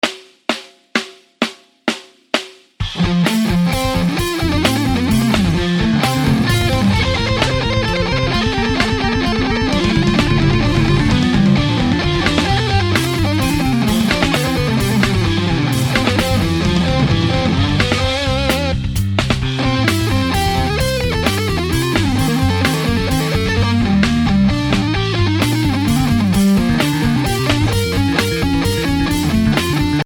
Guitar Tab